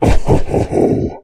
spawners_mobs_balrog_neutral.2.ogg